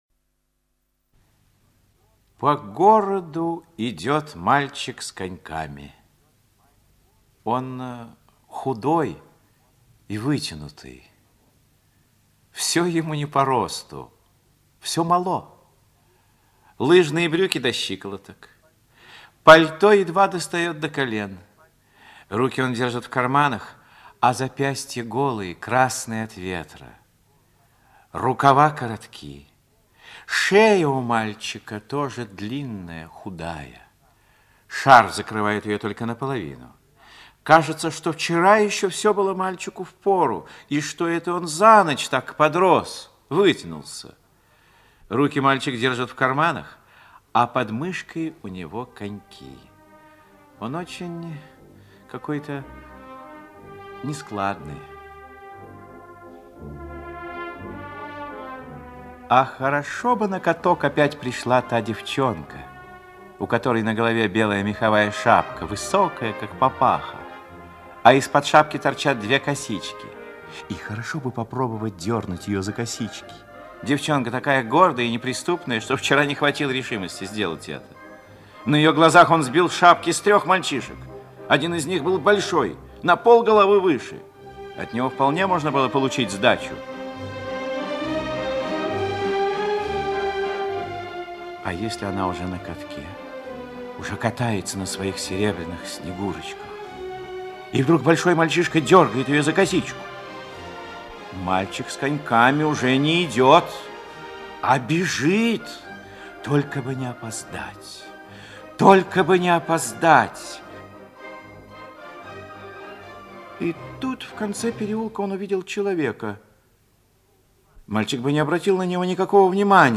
Мальчик с коньками - аудио рассказ Яковлева Ю.Я. Рассказ о том, как мальчик шел на каток и увидел человека, которому стало плохо...